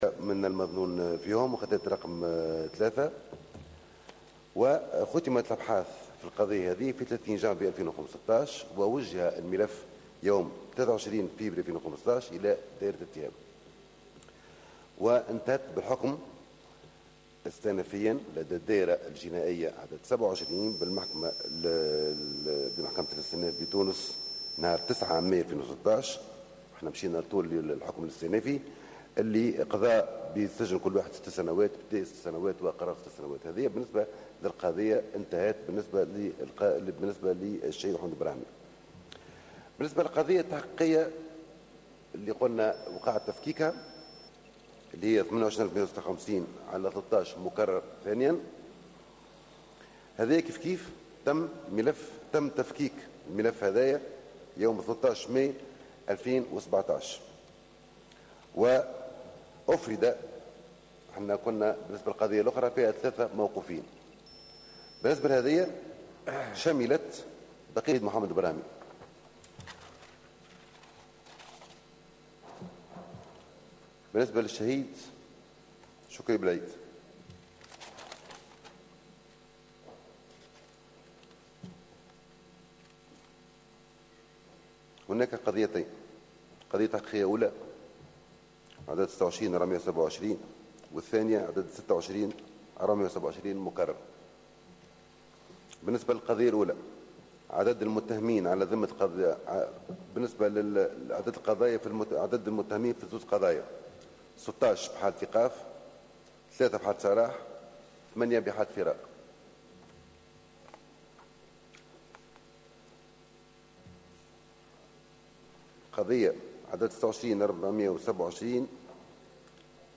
وأوضح في رده على تساؤلات النواب في جلسة برلمانية حول مستجدات ملف اغتيال بلعيد والبراهمي أن هناك 16 موقوفا في قضية شكري بلعيد و3 في حالة سراح و8 في حالة فرار فيما تم ايقاف 3 أشخاص في قضية البراهمي وصادرة في شأنهم أحكام تتراوح بين 6 و7 سنوات.